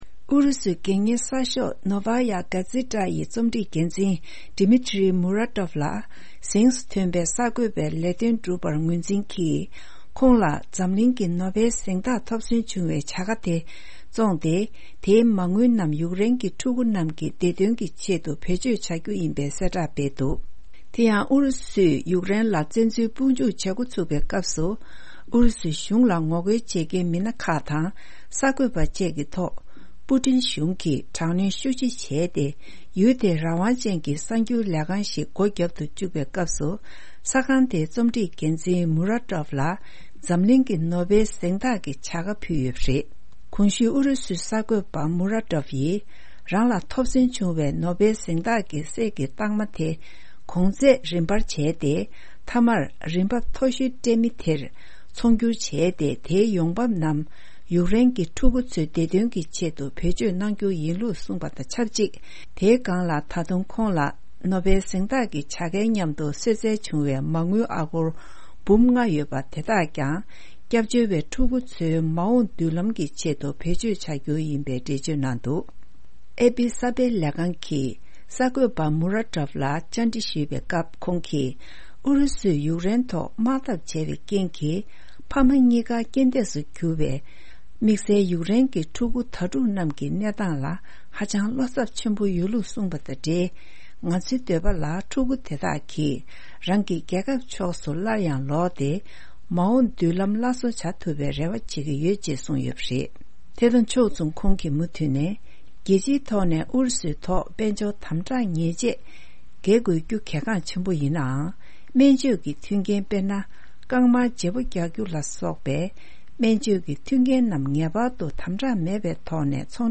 ཕབ་སྒྱུར་དང་སྙན་སྒྲོན་ཞུས་ཡོད།